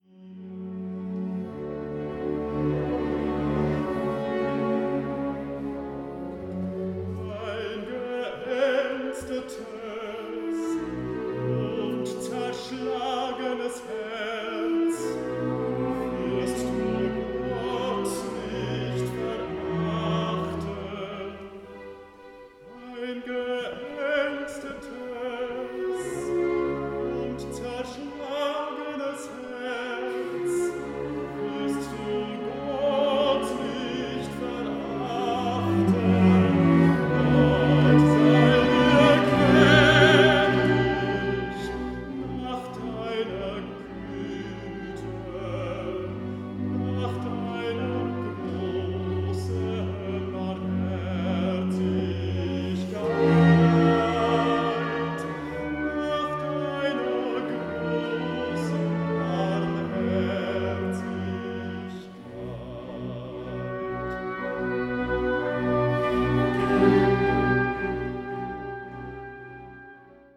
Bariton